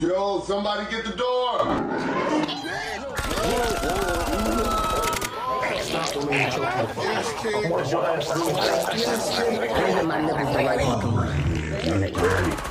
yes king yo somebody get the door Meme Sound Effect